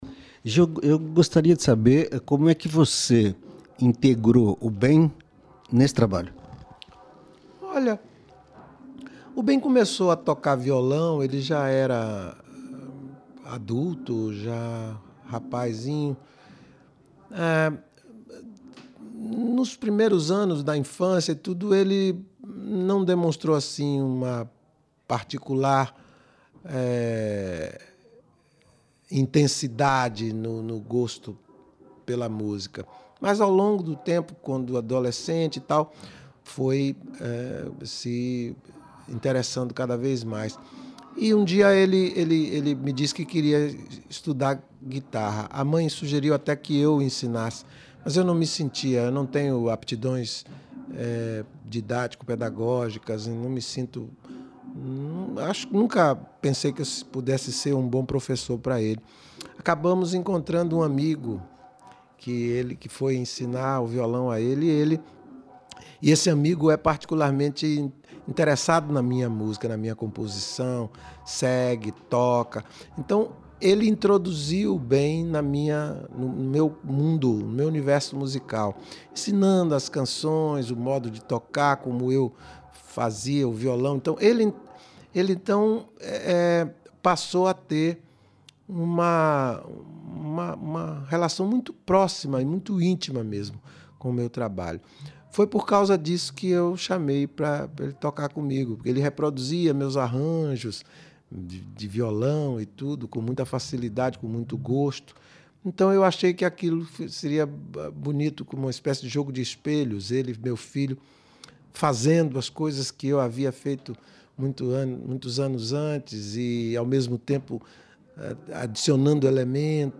Ouça a íntegra da entrevista de Gilberto Gil